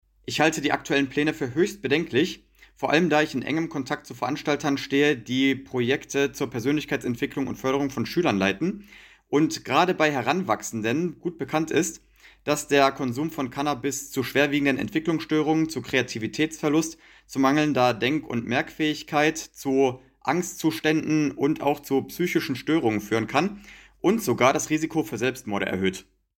Gesundheitsexperte zu Cannabis-Legalisierung